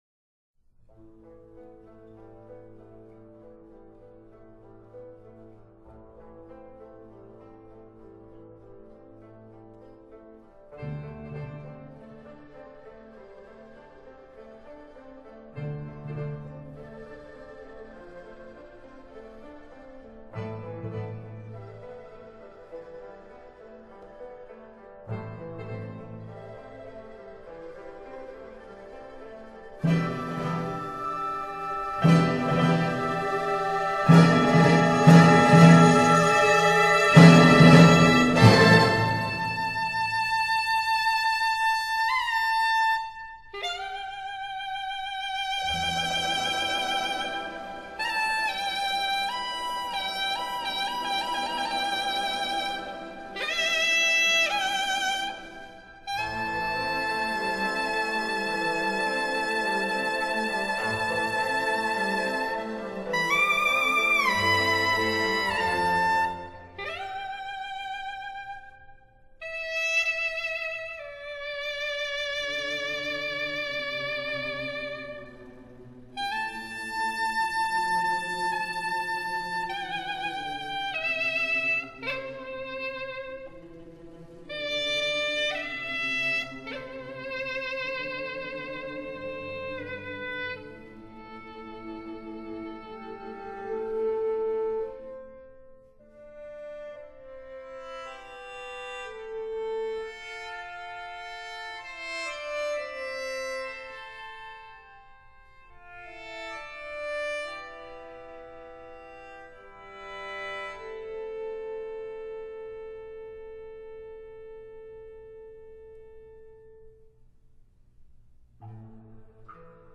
录音地点：台湾高雄市立中正文化中心至德堂